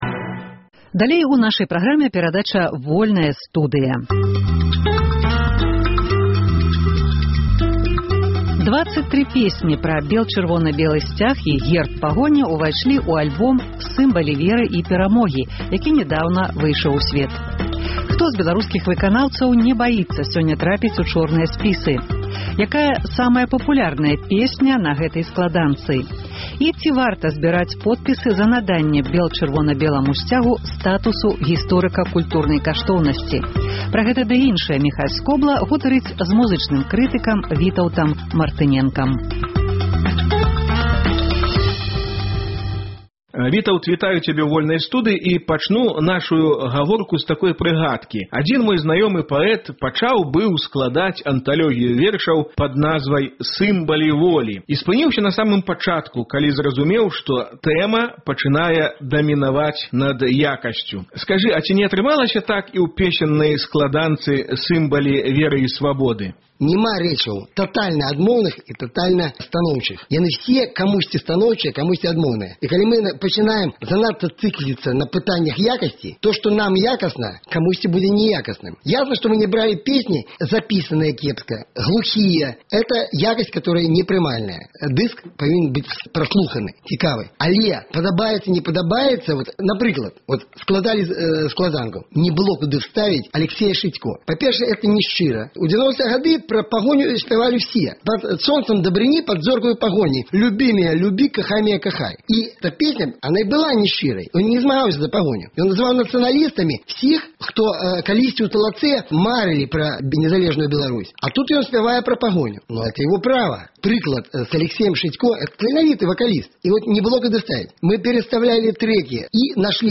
гутарыць з музычным крытыкам